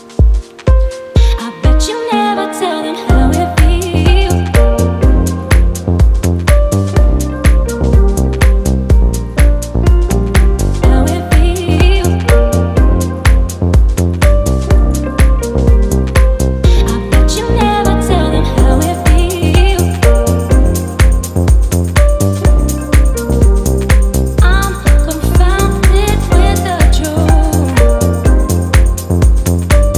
Electronic
Жанр: Электроника